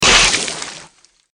Seltzer_squirt_2dgame_hit.ogg